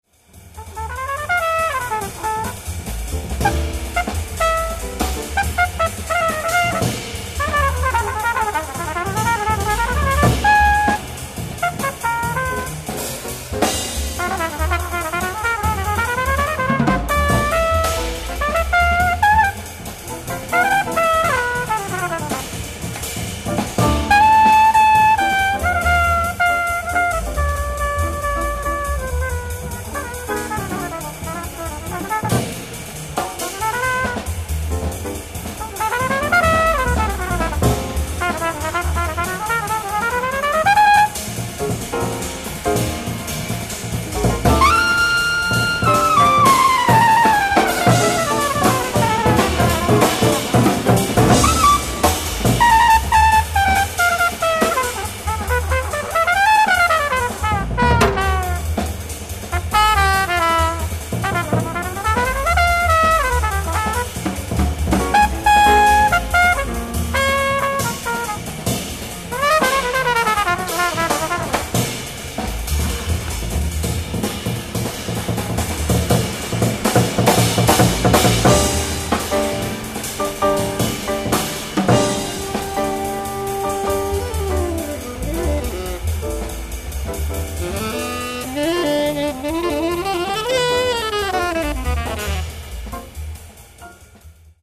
Tracks 1-5:Live At Hensinki, Finland 10/06/1964